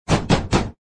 敲门声.mp3